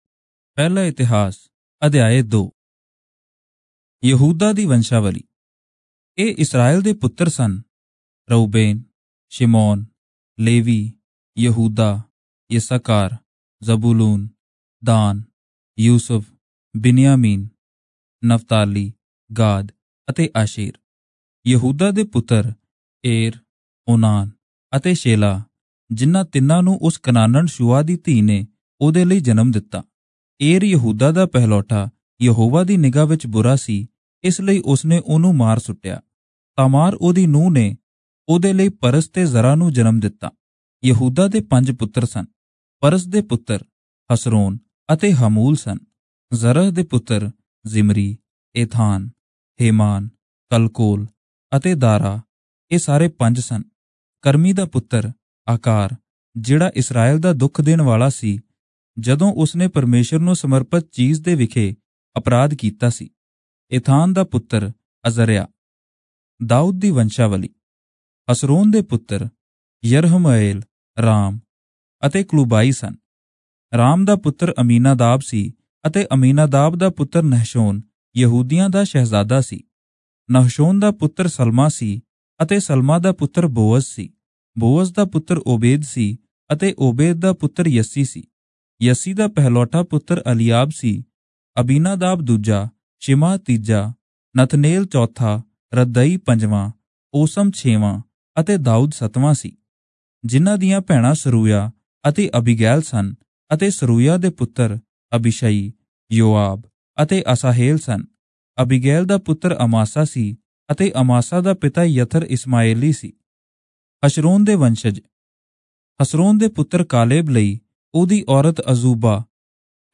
Punjabi Audio Bible - 1-Chronicles 11 in Irvpa bible version